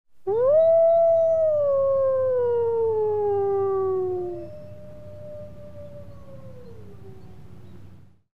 جلوه های صوتی
دانلود صدای گرگ برای کودکان از ساعد نیوز با لینک مستقیم و کیفیت بالا
برچسب: دانلود آهنگ های افکت صوتی انسان و موجودات زنده